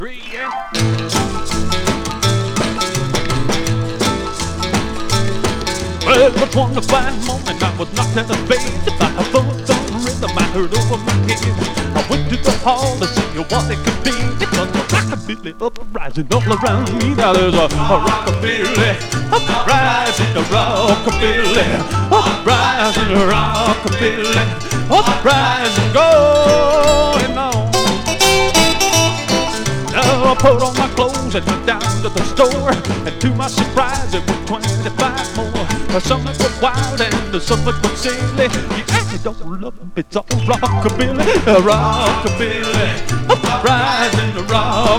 Rock, Rockabilly　USA　12inchレコード　33rpm　Mono